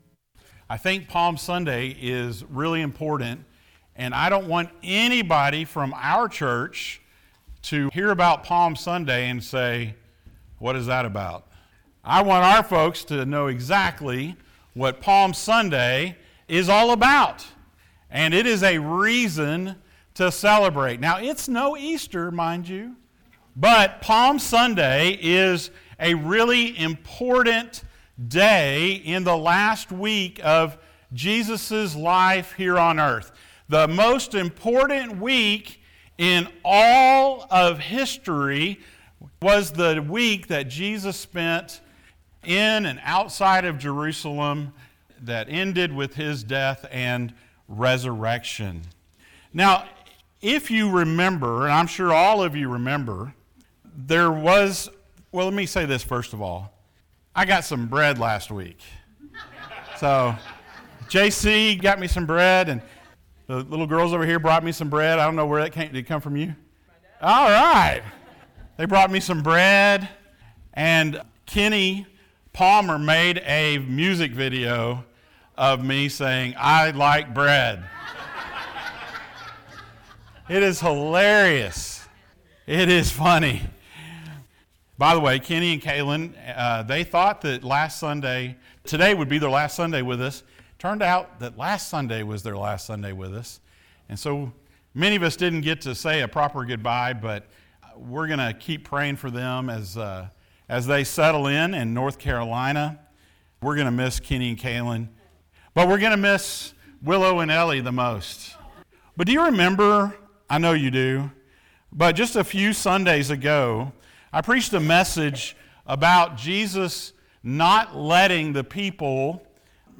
Palm Sunday Passage: Mark 11:1-11 Service Type: Sunday Morning Thank you for joining us.